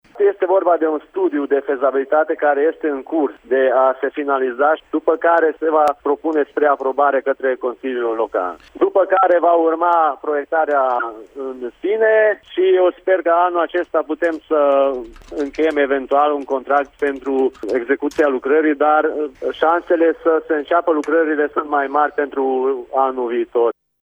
Reamenajarea cartierului Spicul, din municipiu a fost supusă unei dezbateri publice, săptămâna trecută, iar ideile bune au fost incluse în planul de fezabilitate care, încă este în lucru, a explicat viceprimarul din Miercurea Ciuc, Szöke Domokos: